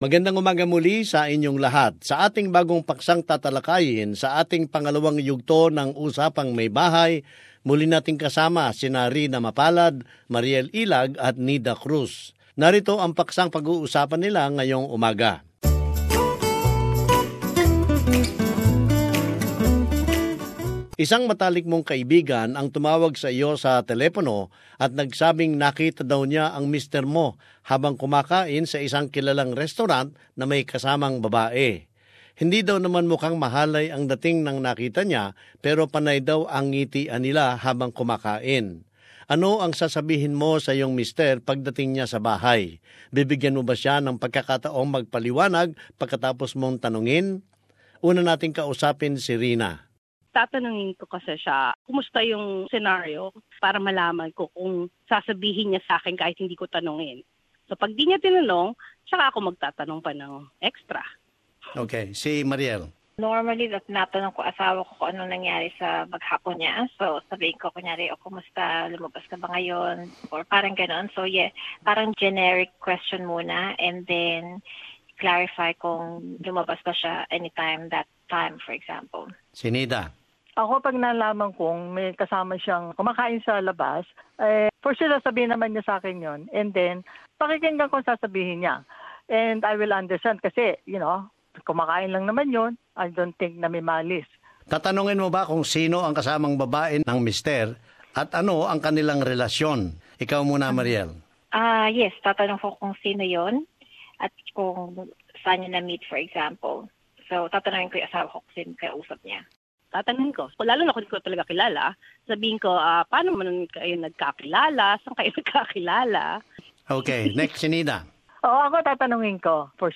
This is the topic that was discussed between three housewives, in connection with our weekly feature of Housewife Conversations